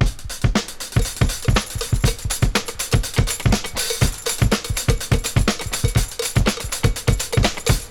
• 121 Bpm HQ Drum Loop Sample E Key.wav
Free breakbeat - kick tuned to the E note. Loudest frequency: 1625Hz
121-bpm-hq-drum-loop-sample-e-key-nQm.wav